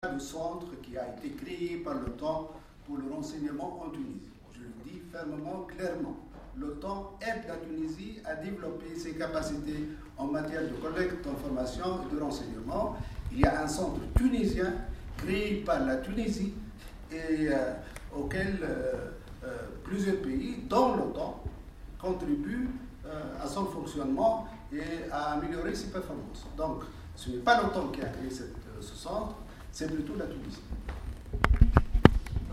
نفى وزير الخارجية خميس الجهيناوي في تصريح